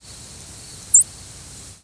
arched: Refers to a call that descends then rises (or vice-versa) in pitch.
upward-arched Chipping Sparrow flight call                    downward-arched Gray-cheeked Thrush flight call